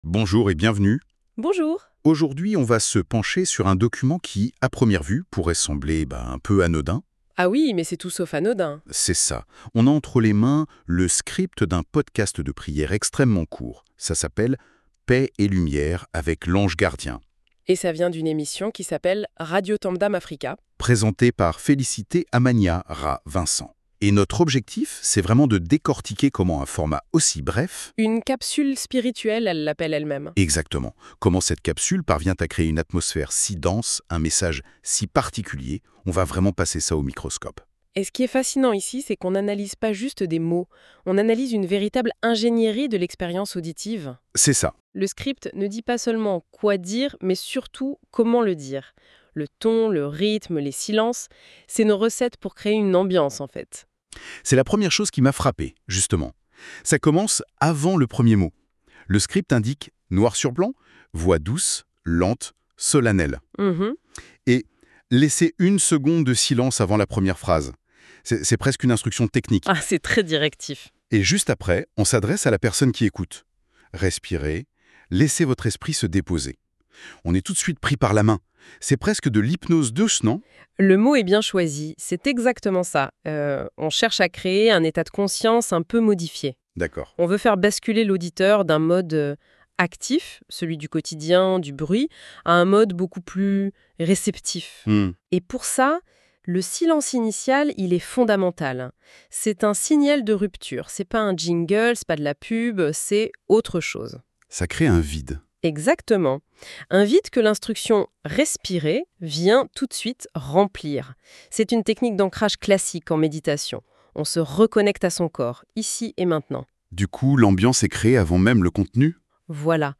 Podcast de prière & méditation
L’émission se referme sur une vibration rassurante et lumineuse, remerciant tous ceux qui ont partagé cet instant sacré.